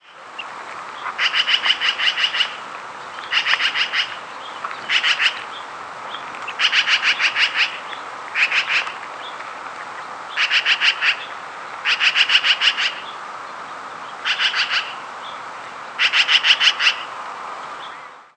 Black-billed Magpie Pica hudsonia
Flight call description A series of typically three to six evenly-spaced, husky, rising notes, delivered at a quick pace of about six notes per second.
Examples Diurnal calling sequence: 1.
Perched bird with House Finch calling in the background.